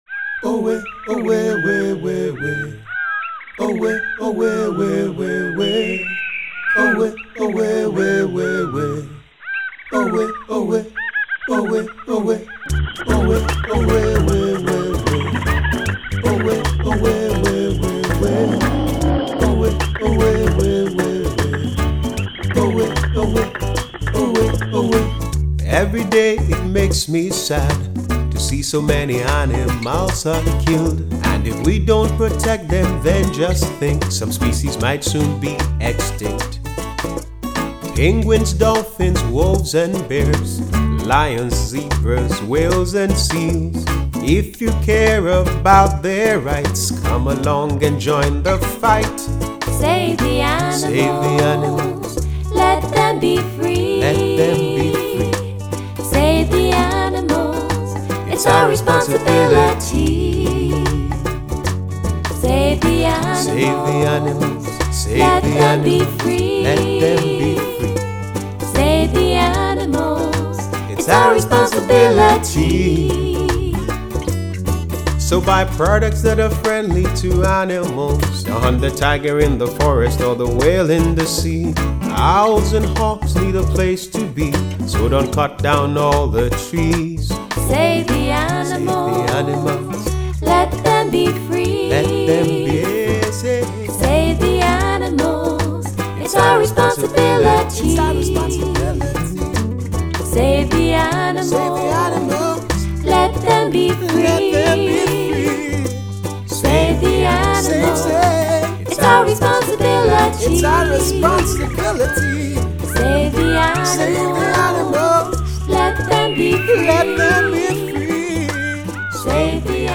style: reggae